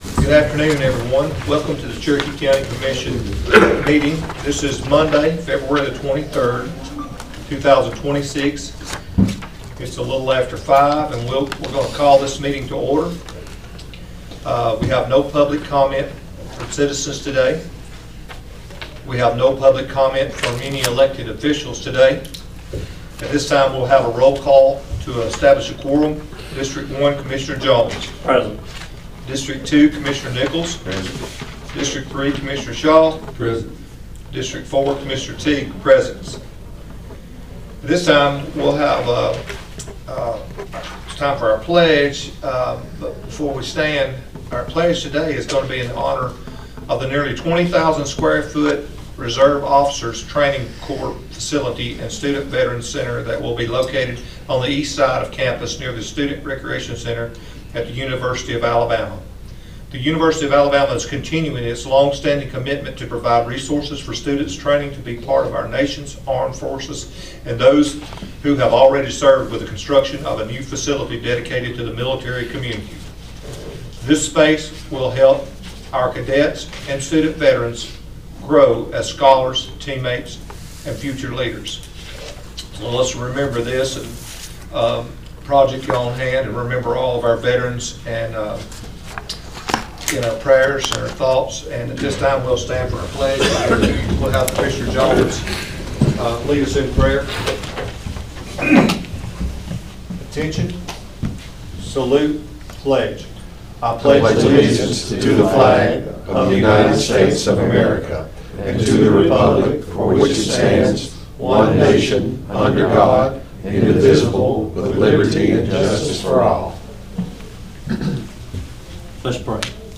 Cherokee County Commission Meeting (02/23/2026)
No public hearings or citizen comments were held during the meeting, and officials reported no additional departmental updates.